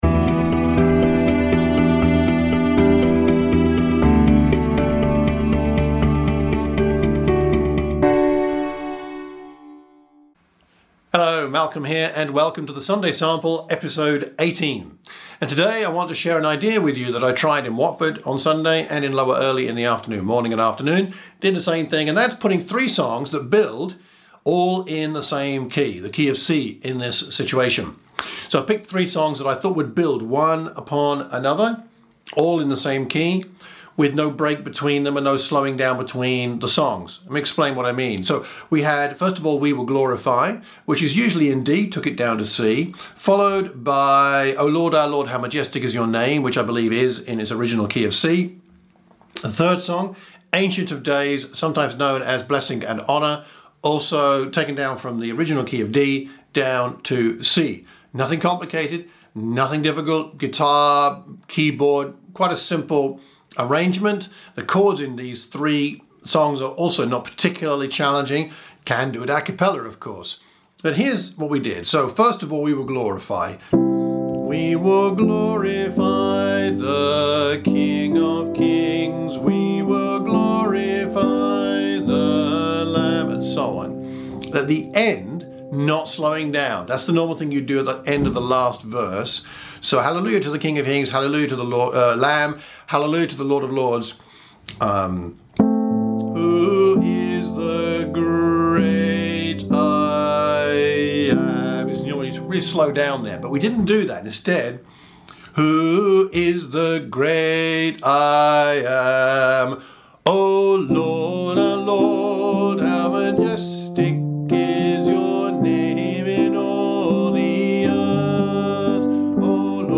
Three songs in C as an opening set.